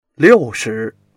liu4shi2.mp3